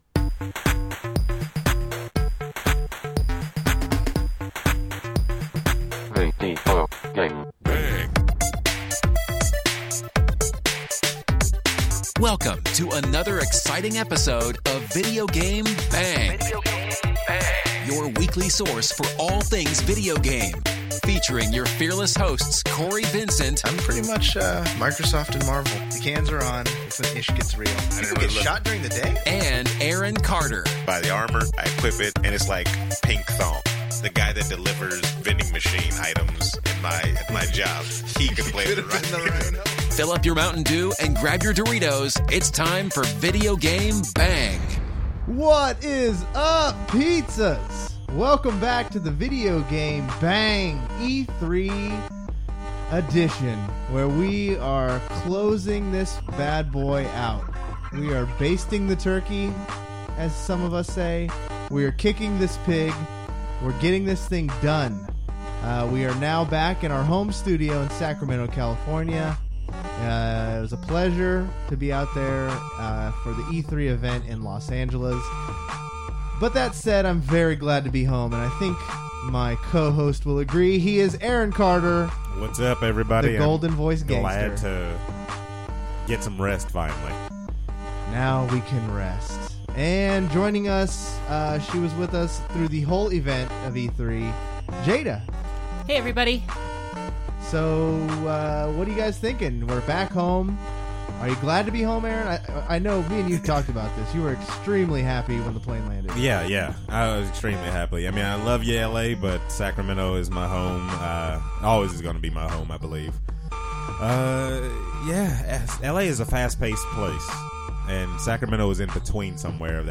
Past are the days of recording in our cheap LA hotel room and back are we to the lush studio in the great capital of California.